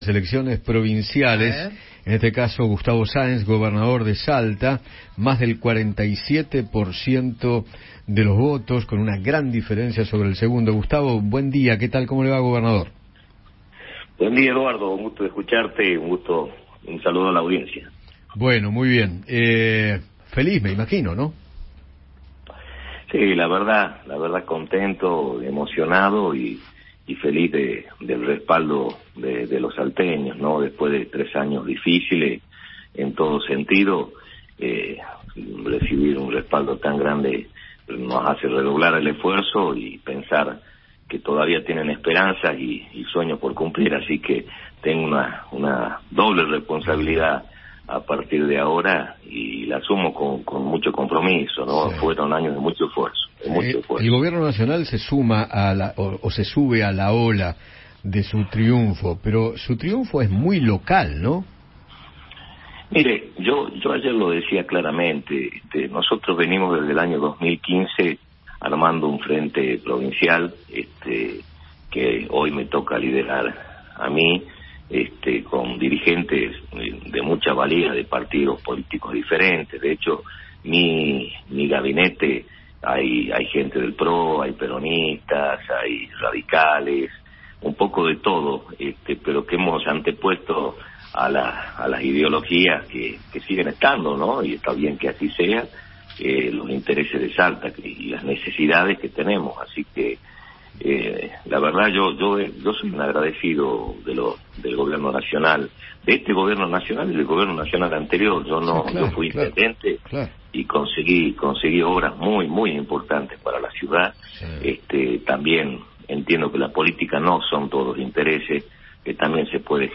Gustavo Saénz, actual gobernador de Salta, conversó con Eduardo Feinmann sobre el resultado de las elecciones de este domingo, donde fue reelecto con más del 47% de los votos.